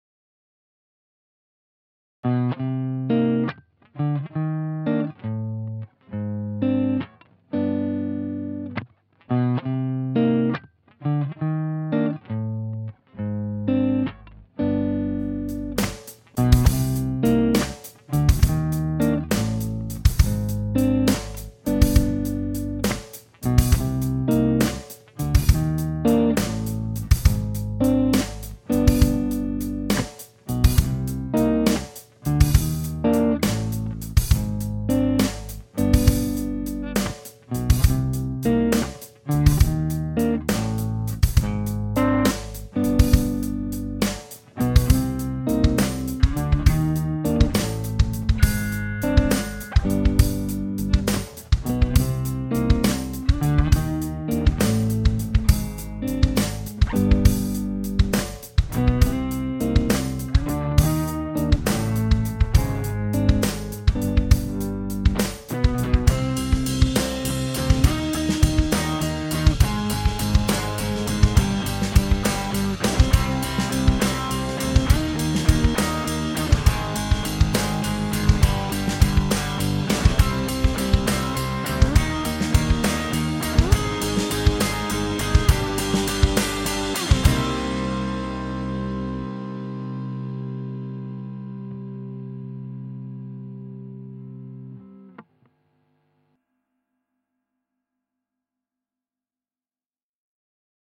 Backing track link at bottom of page